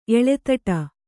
♪ eḷetaṭa